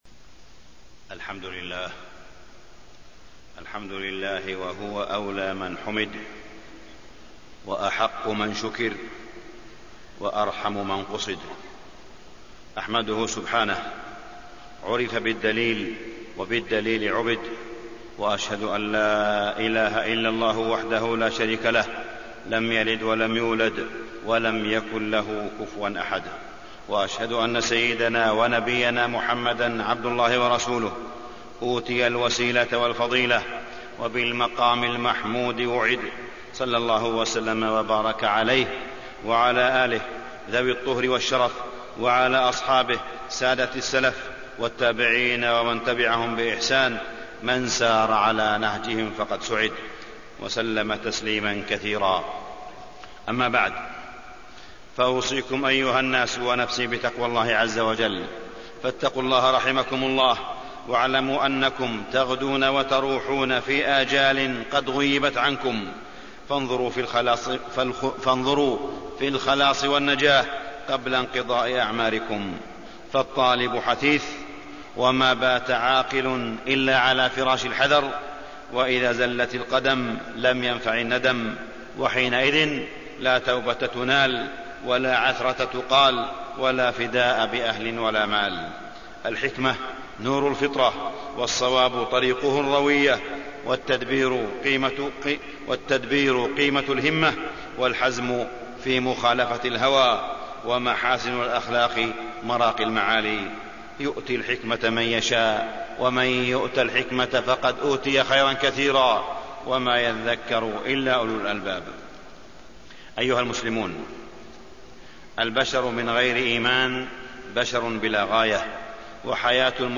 تاريخ النشر ١٥ جمادى الأولى ١٤٢٨ هـ المكان: المسجد الحرام الشيخ: معالي الشيخ أ.د. صالح بن عبدالله بن حميد معالي الشيخ أ.د. صالح بن عبدالله بن حميد الإيمان والمحبة